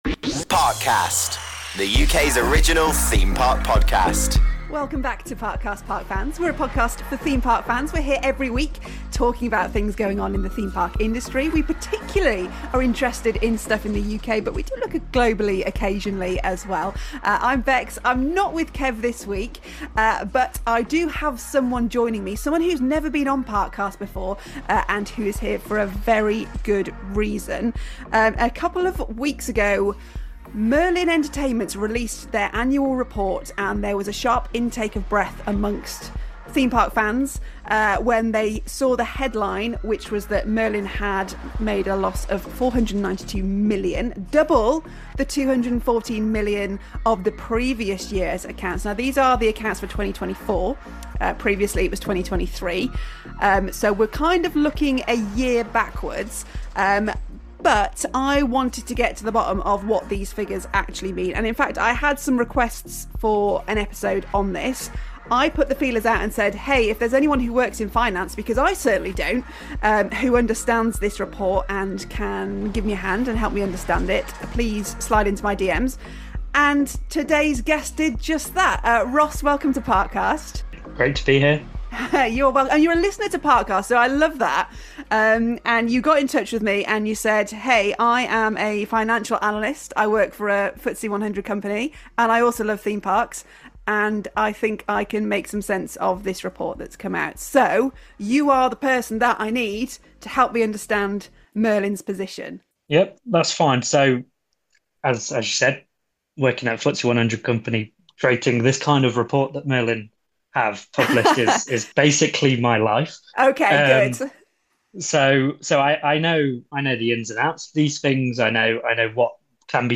a financial analyst for a FTSE 100 company who explains what we are actually looking at and why things are not actually all that they seem…..